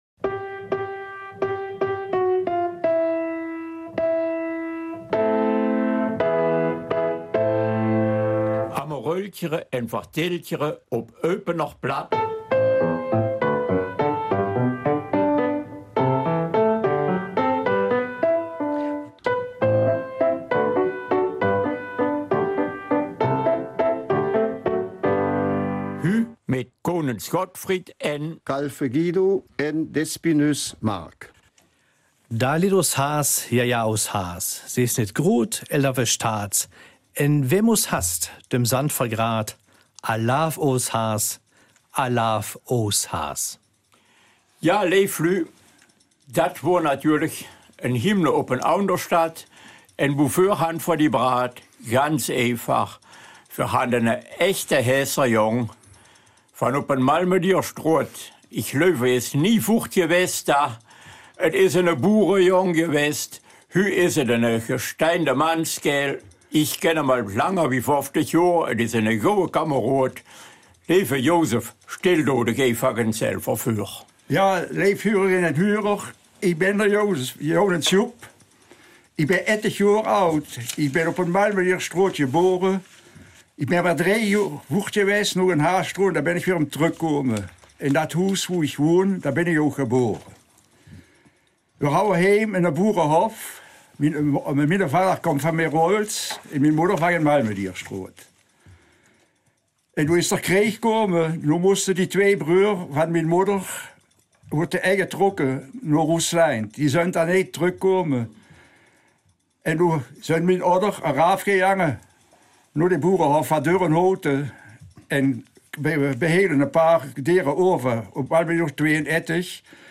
Eupener Mundart